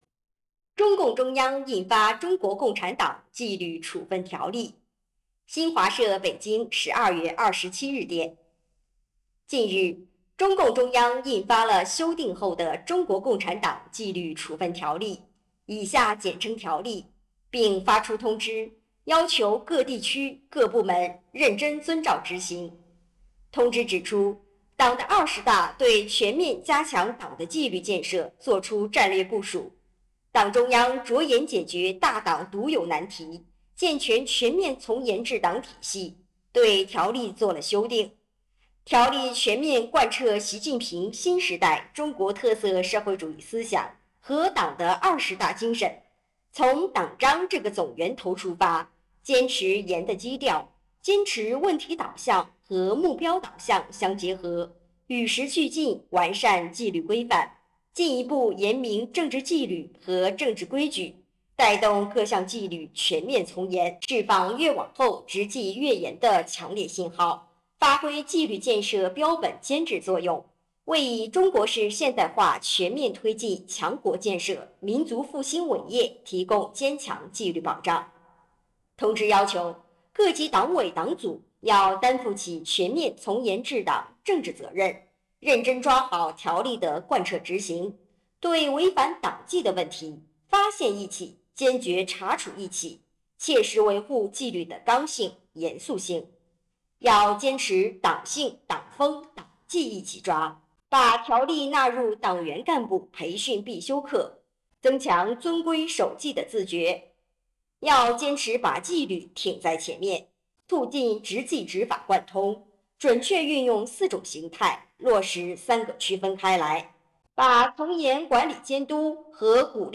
菏泽医专附属医院开展党纪学习教育有声诵读活动 （第一期）
医院党委决定开展党纪学习教育有声诵读活动，由各党支部对《中国共产党纪律处分条例》进行原文诵读，定期推送章节音频，引导广大党员干部对照《条例》各项规定检视问题和不足，用党规党纪校正思想和行动。